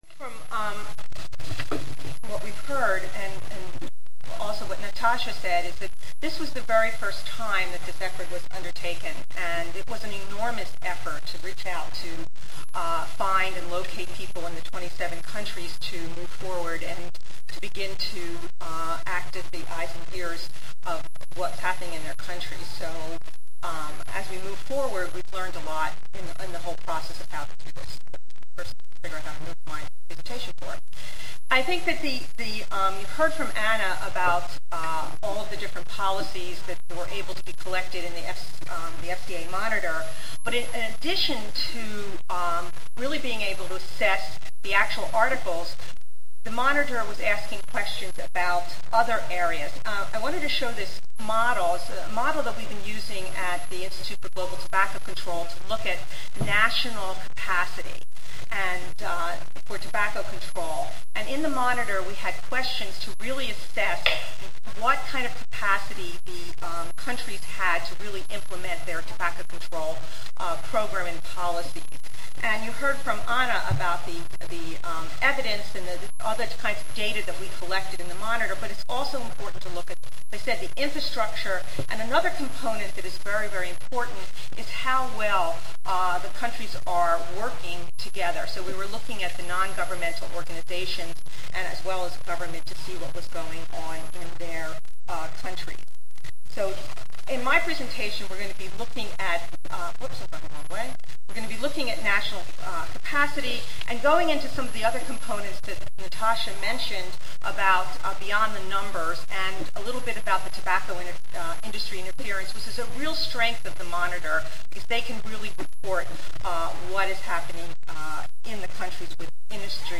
3093.0 Assessing Implementation of the FCTC: The FCA FCTC Monitor Monday, November 5, 2007: 10:30 AM Oral The Framework Convention on Tobacco Control (FCTC) is the first global health treaty negotiated under the auspices of the World Health Organization.